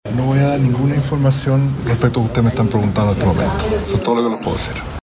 Frente a esta situación, el fiscal Cooper se pronunció públicamente por primera vez a la salida de la tercera jornada de recepción de pruebas relacionadas con el Caso ProCultura, en la que diversos parlamentarios del oficialismo habían solicitado formalmente su remoción de las investigaciones.